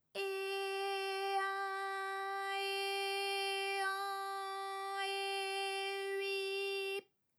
ALYS-DB-002-FRA - First publicly heard French UTAU vocal library of ALYS